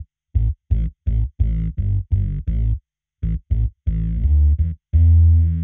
Index of /musicradar/dub-designer-samples/85bpm/Bass
DD_JBassFX_85A.wav